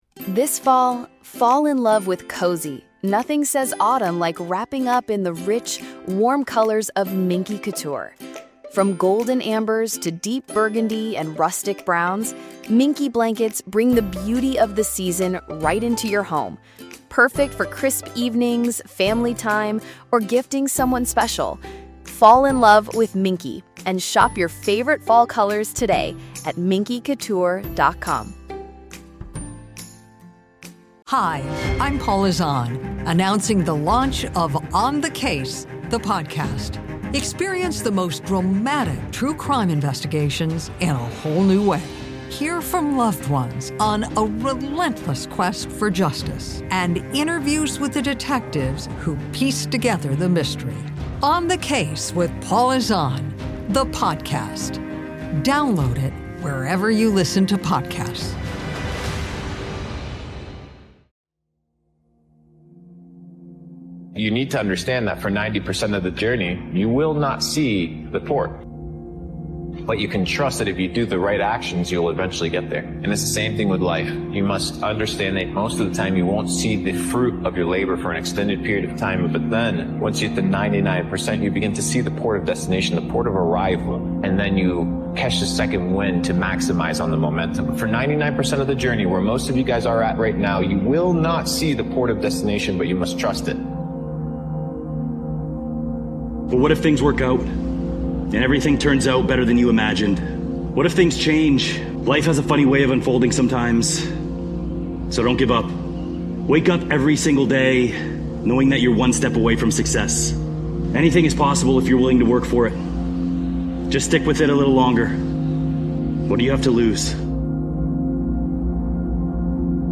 Discover how to transform your life in just 6 months with this motivational speech.